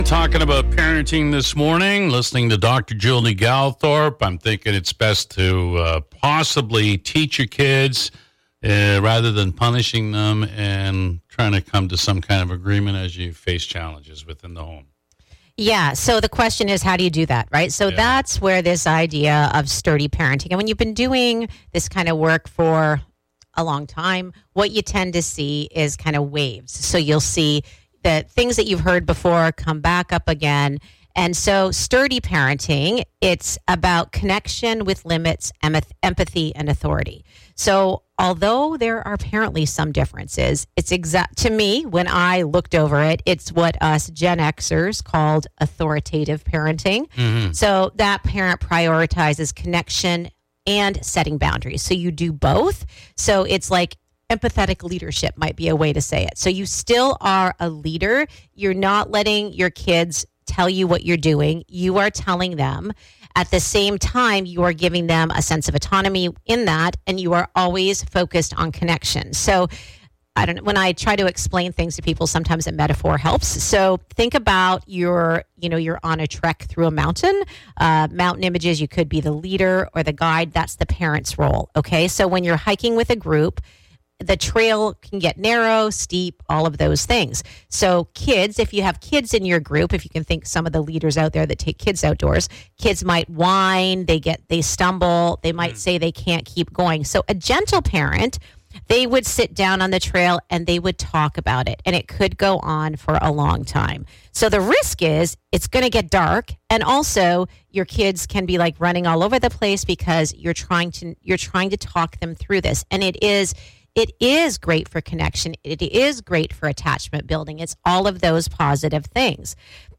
in Studio 97